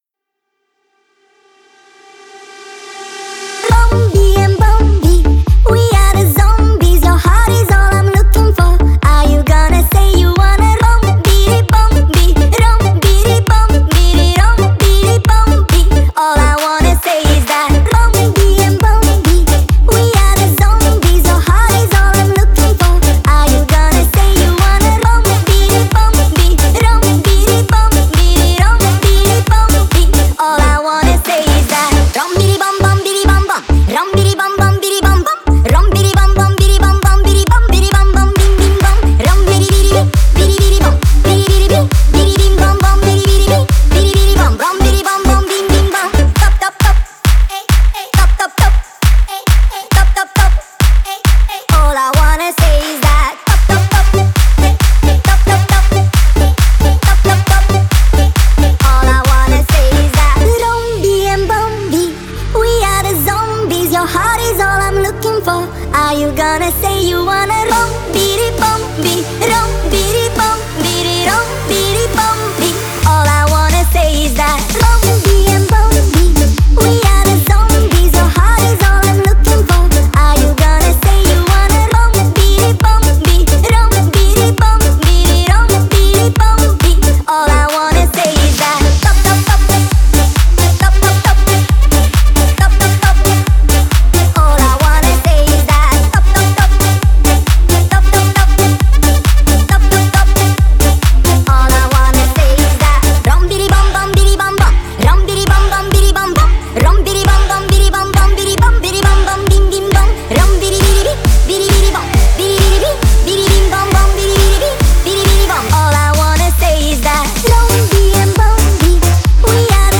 Веселая песенка к Хеллоуину